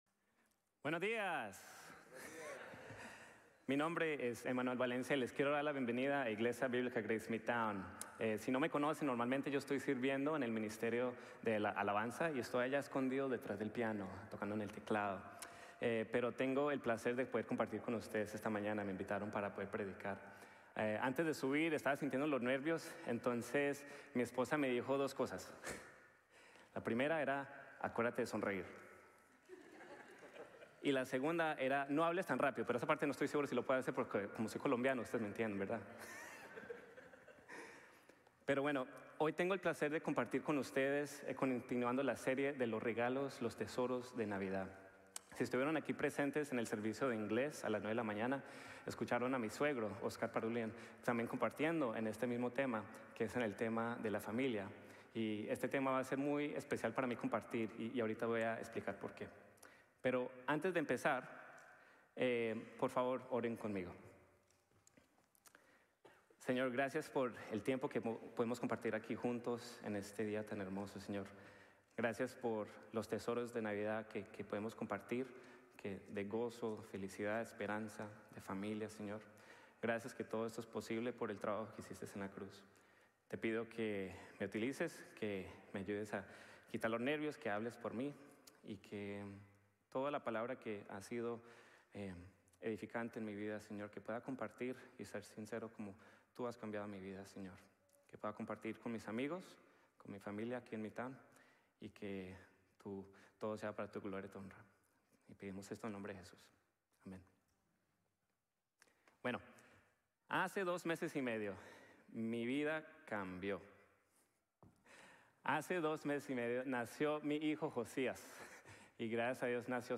Levántate y Cuida tu Familia | Sermon | Grace Bible Church